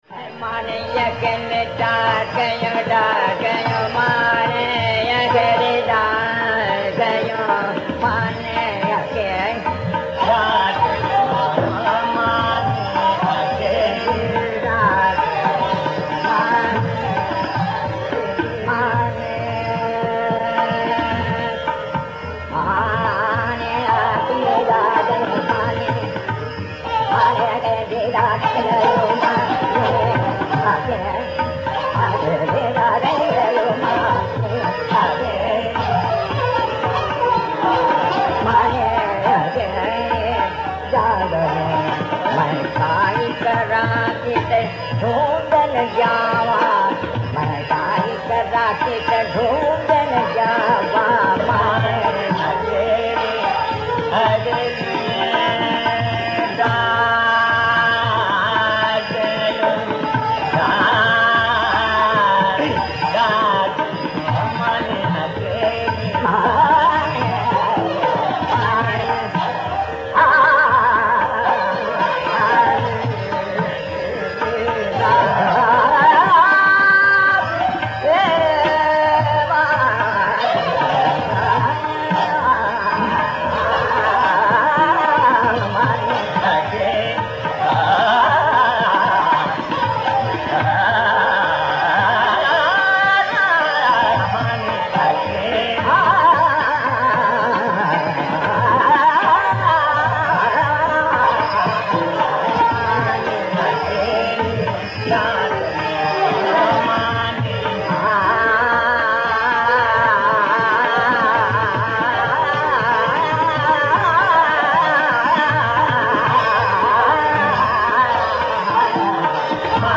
The Jait thread is made up of the clusters S G, G P and P-S”-P, and is knitted on the Shree fabric.